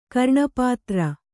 ♪ karṇapātra